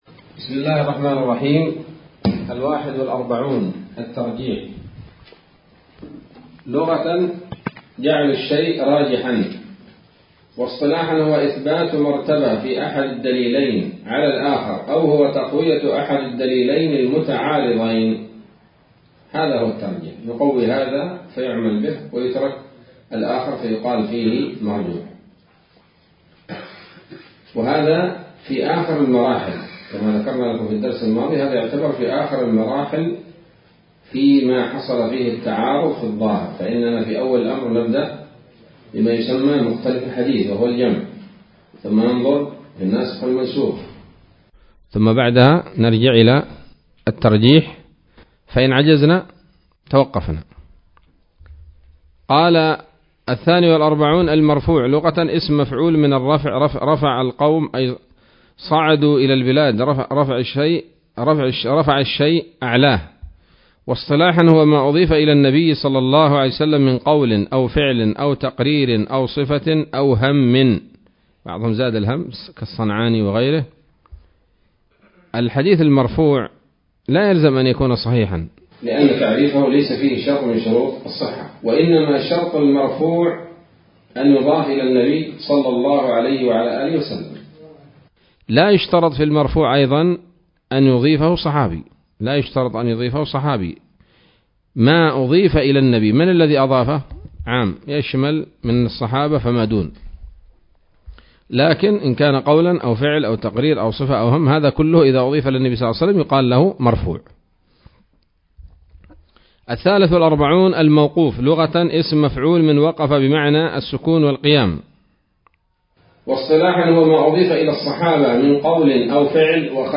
الدرس السادس من إرشاد الثِّقات إلى أهم التعريفات في علم الأحاديث النبويات